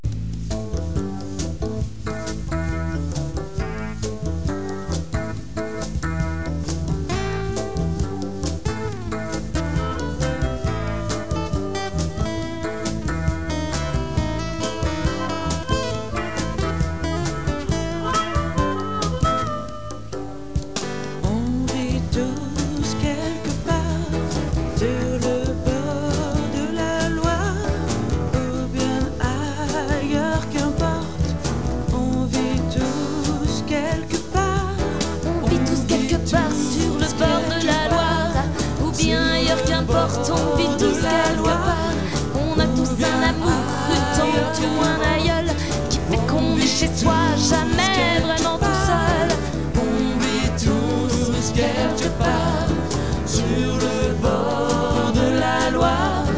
Musique Ethnique de la Basse Vallée de l'Ourcq